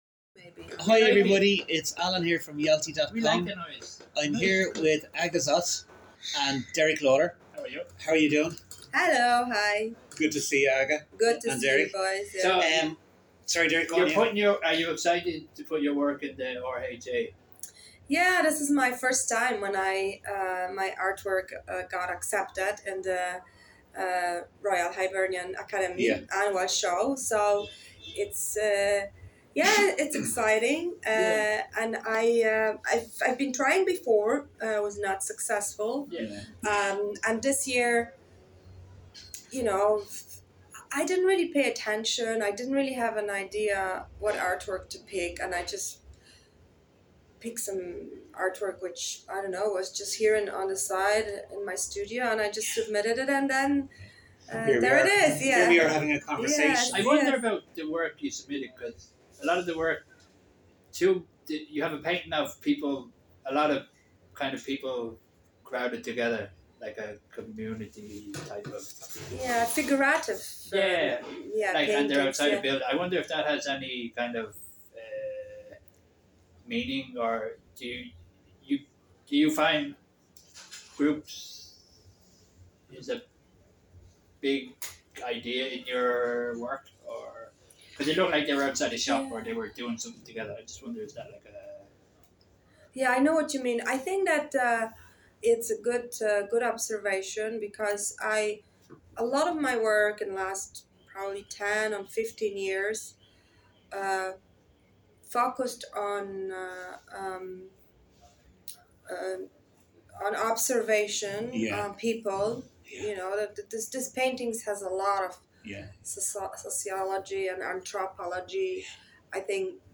Interview
Warning: interview contains some strong language.